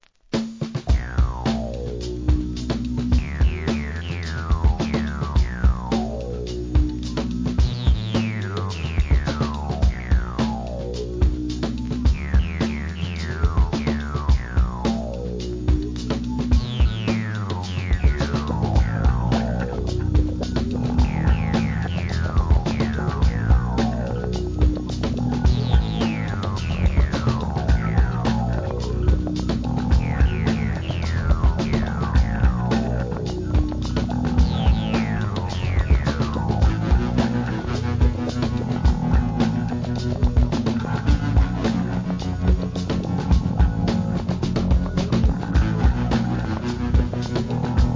AMBIENT,ブレイクビーツ!!